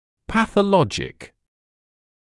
[ˌpæθə’lɔʤɪk][ˌпэсэ’лоджикл]патологический